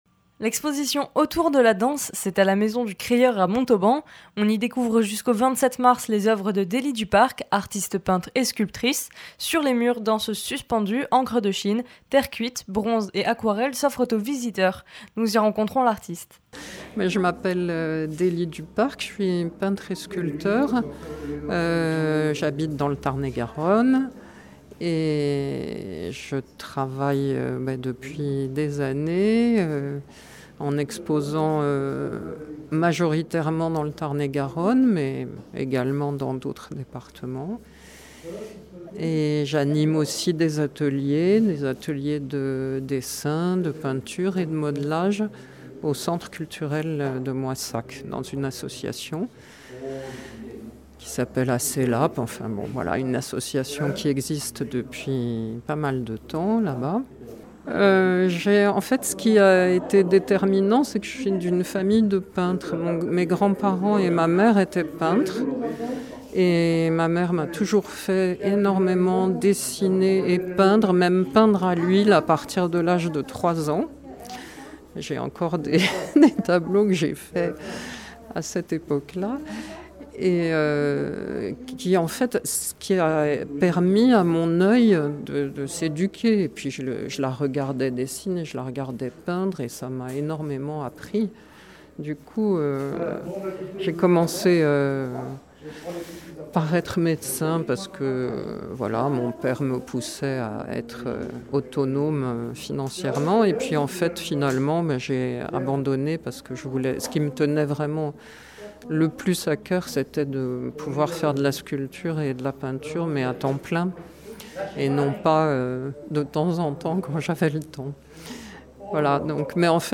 Interviews
Reportage à l’exposition "Autour de la danse" à la maison du crieur de Montauban